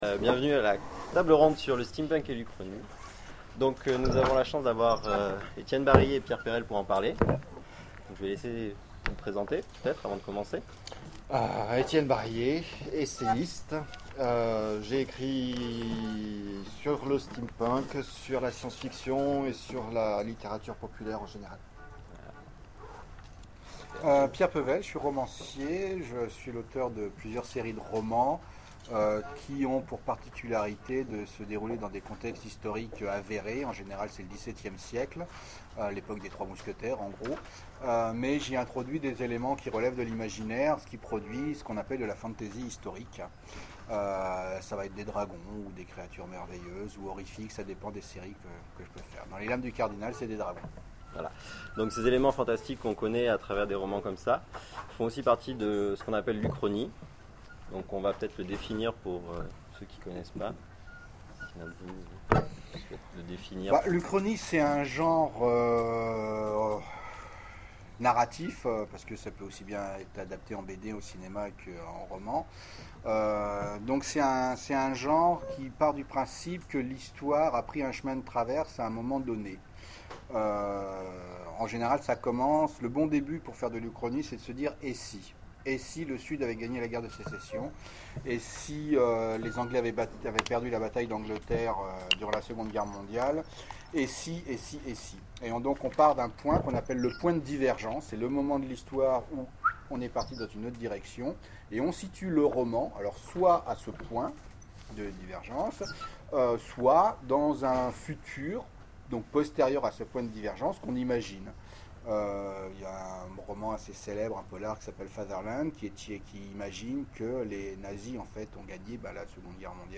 Oniriques 2013 : Conférence Rencontre autour du steampunk et de l'uchronie